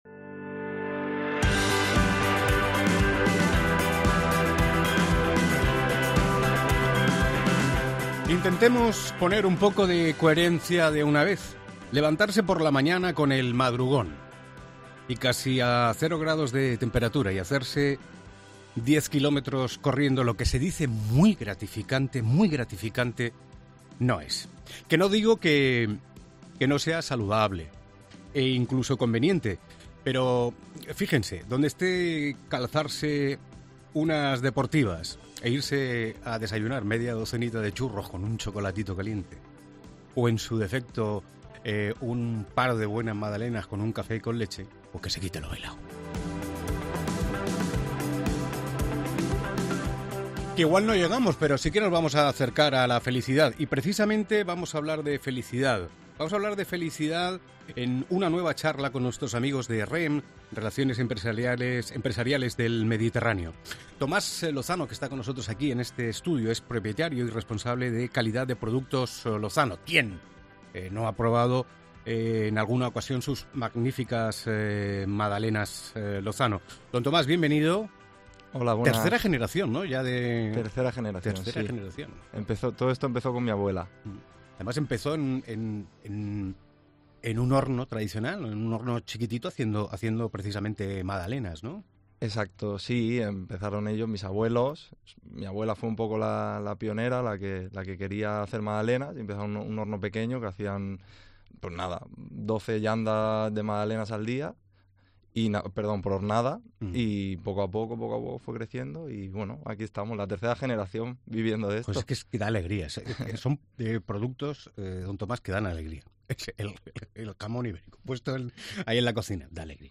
Redacción digital Madrid - Publicado el 27 ene 2023, 10:58 - Actualizado 27 ene 2023, 12:49 1 min lectura Facebook Twitter Whatsapp Telegram Enviar por email Copiar enlace Nueva charla con nuestros amigos de Relaciones Empresariales del Mediterráneo .